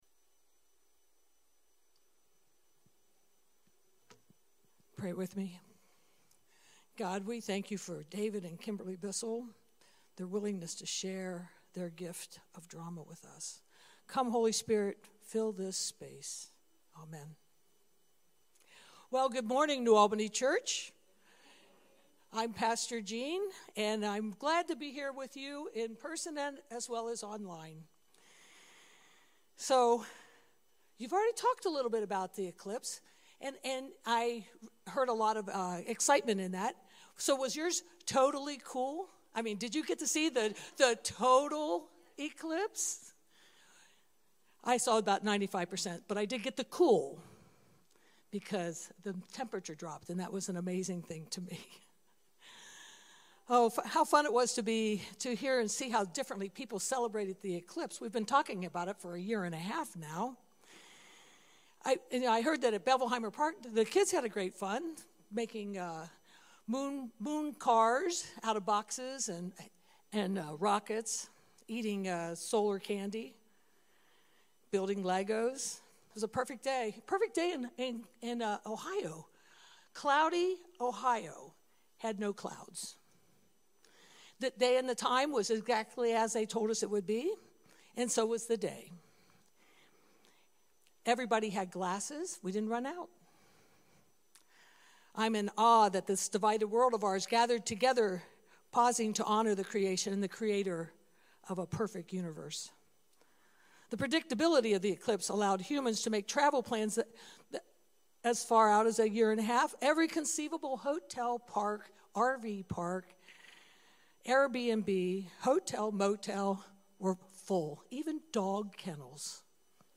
April 14, 2024 Sermon